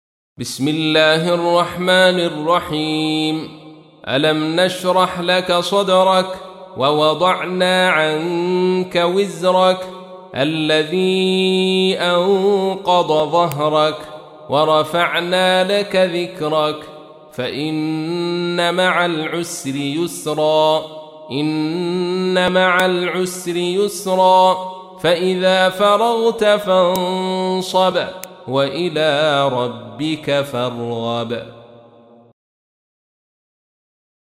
تحميل : 94. سورة الشرح / القارئ عبد الرشيد صوفي / القرآن الكريم / موقع يا حسين